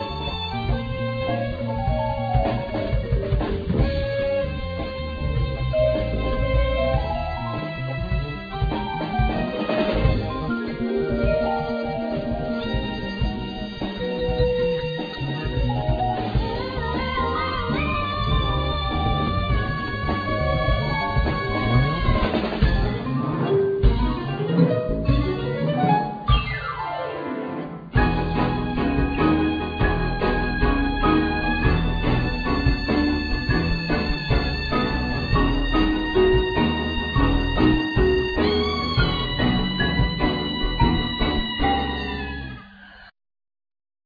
Accordeon,Vocals
Violin,Viola
Organ,Grand Piano,Pianet,Synthesizer,Vocals
Electric Basse,Bow,Darbuka,Guiro
Vibraphone,Marimba,Glockenspiel,Percussion
Drums,Bongos and Blocks